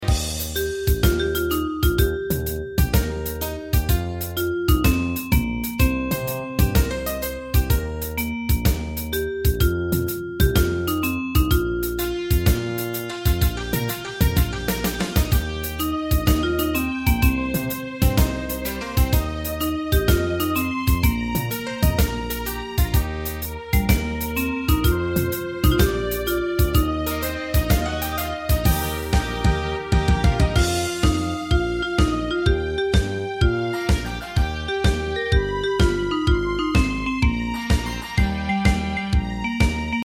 Unison musical score and practice for data.
Tags: Japanese , Kayokyoku Enka .